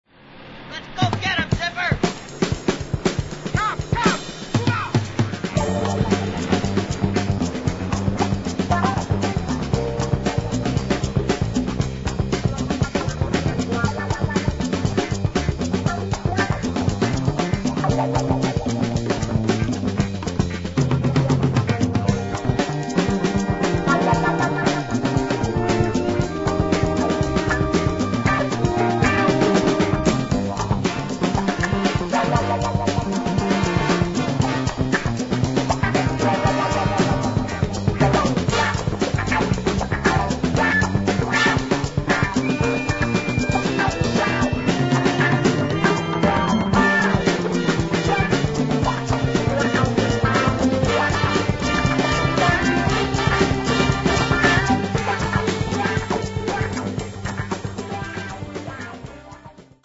One of the best hard funk chase themes you'll find.
over a fat breakbeat.